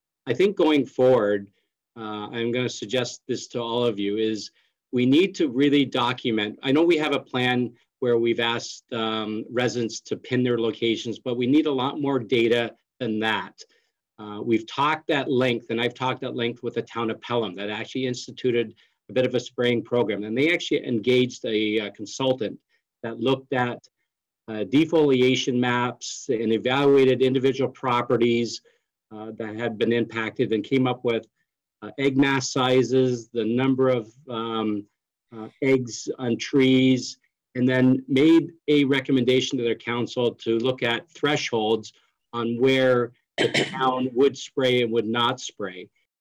At Monday’s council meeting, council reaffirmed its commitment to reaching out to nearby municipalities to push for a regional approach on spraying to combat the problem.